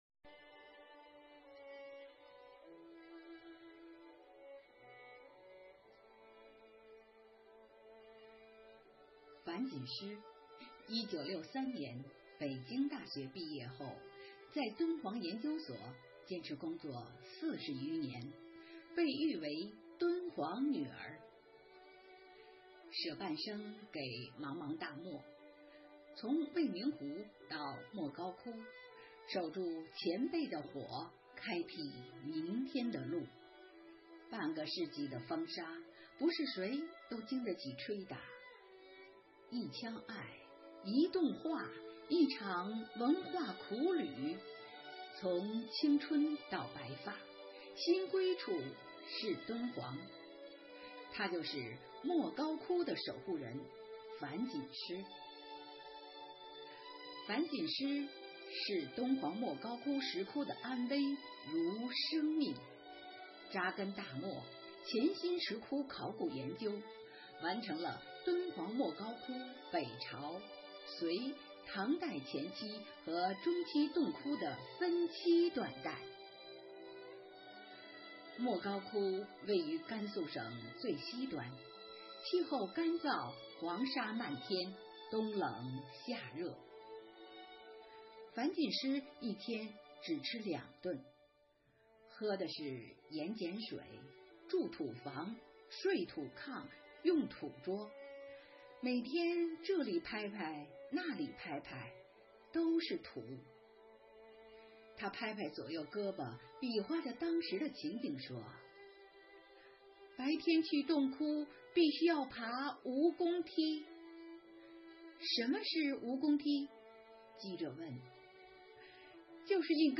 五一劳动节来临之际，为致敬最美劳动者，4月28日，生活好课堂幸福志愿者魅力之声朗读服务（支）队举办“致敬最美劳动者 一一我心中的故事”云朗诵会。